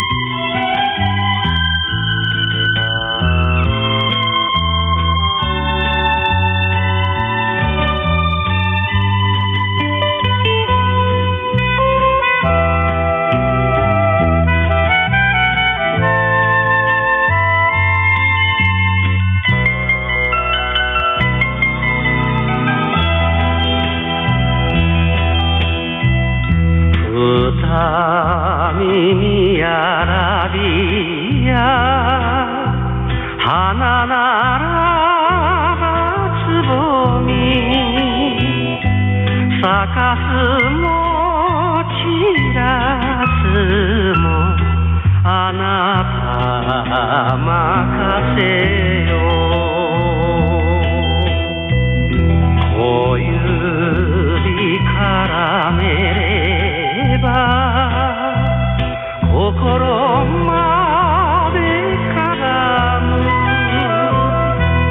受信音を録音してみました。
いずれもANT端子信号レベル＝約S9+20ｄBで、AGC-VR＝若干下げている。
①　送信モード＝ISB、帯域＝3.5KHｚ　　　受信機モード＝ISB、LPF=3.6KHｚ
ISB受信録音＝1分間
LSB＝モノラル音、ISB=ステレオ音となります。